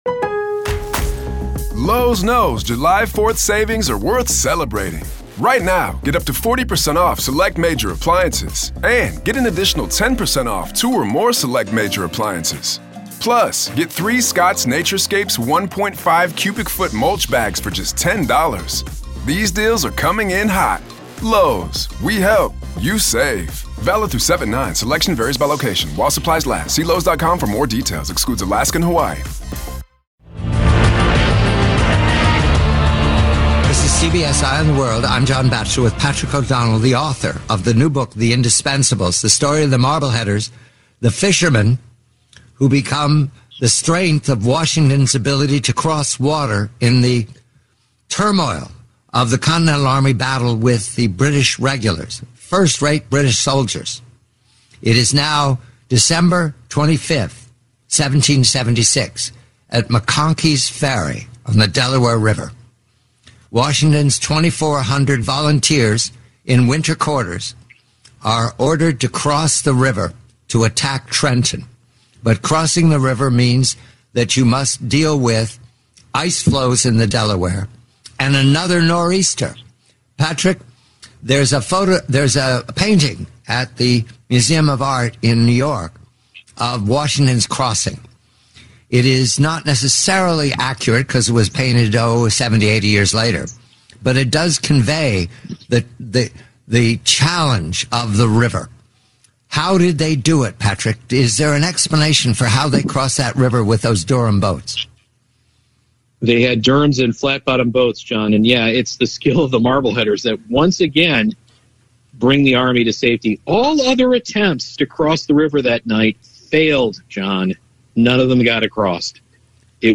The complete, 80-minute interview